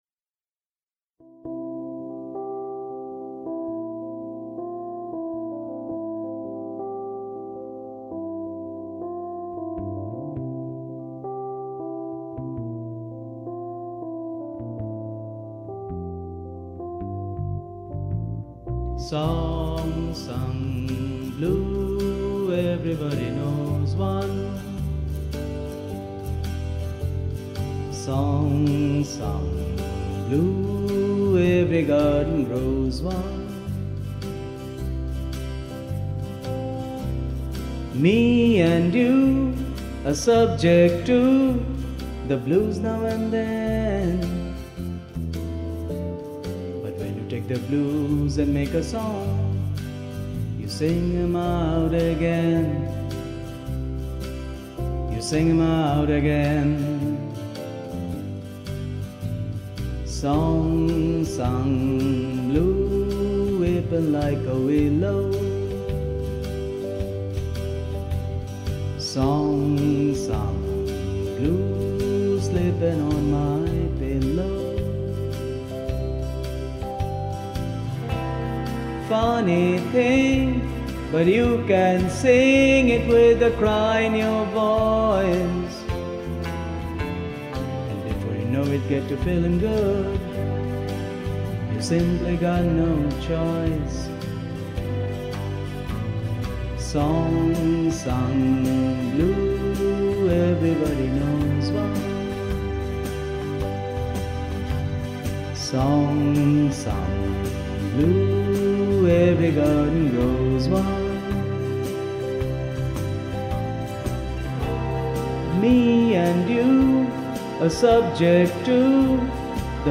They are lovely people who make spiritual music.
I have taken the liberty of adding slight compression to the track and a bit of reverb.